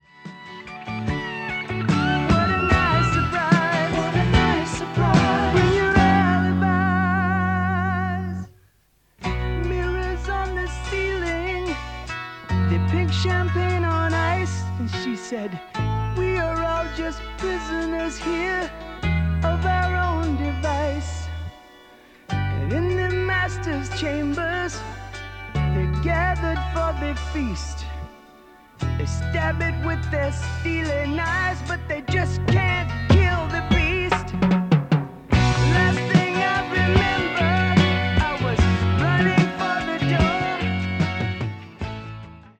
Below is a recording made by the TA-2027 and played back by it:
Onkyo-TA-2027-Test-Recording.mp3